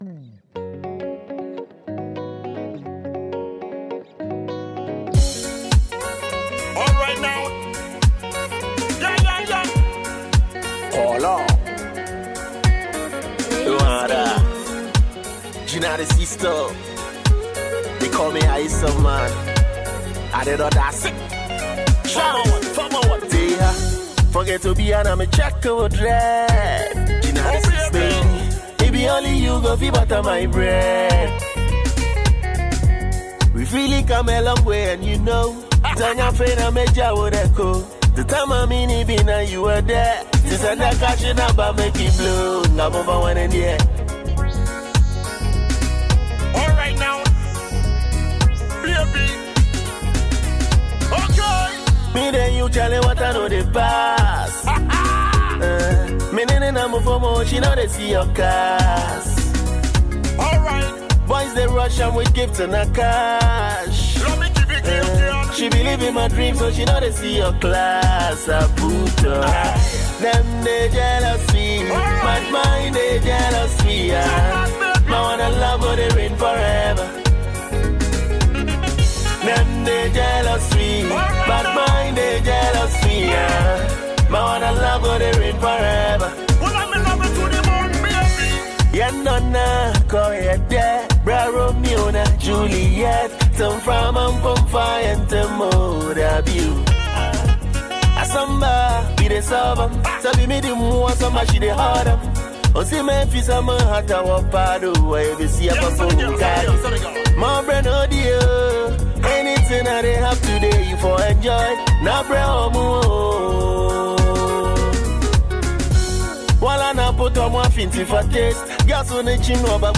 Ghanaian singer
Enjoy this Ghana mp3 music and download free mp3 song audio.